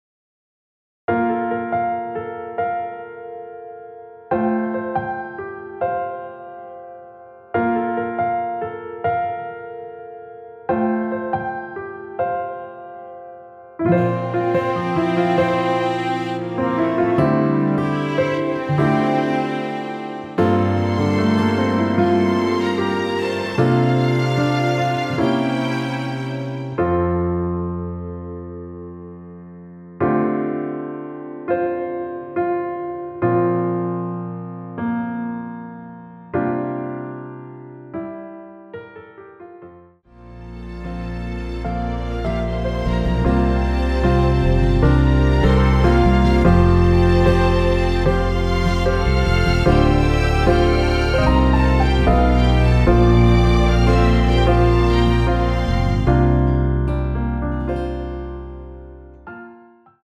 남성분이 부르실수 있는키의 MR입니다.(미리듣기 확인)
원키에서(-8)내린 MR입니다.
앞부분30초, 뒷부분30초씩 편집해서 올려 드리고 있습니다.
중간에 음이 끈어지고 다시 나오는 이유는